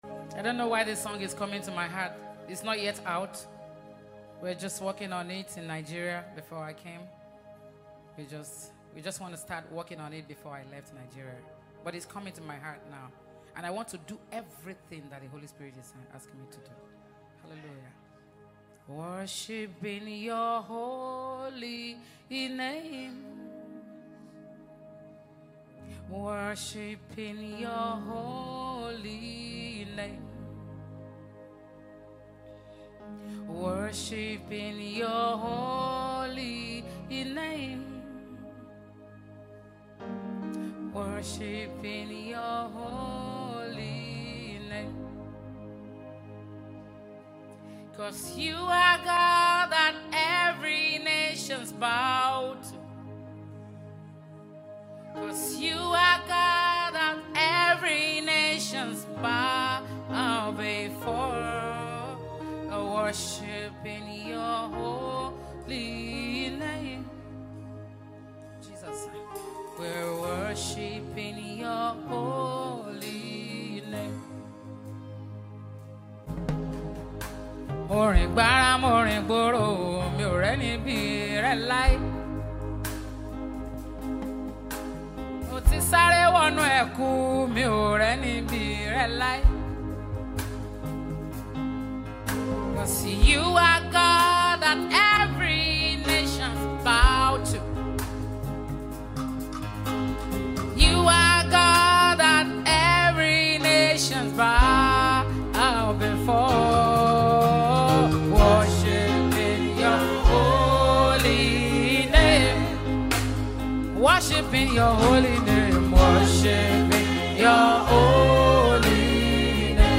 worship sound